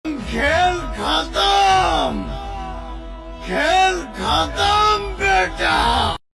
Pawan singh saying khel khatam, khel khatam beta